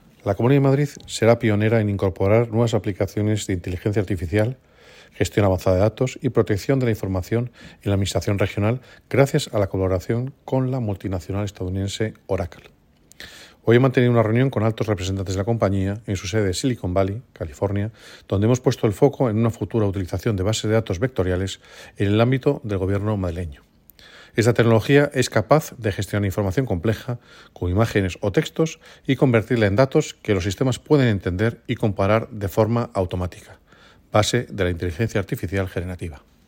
Declaraciones del consejero en la página web de la C. de Madrid con la nota de prensa]